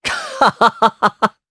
Esker-Vox_Happy3_jp.wav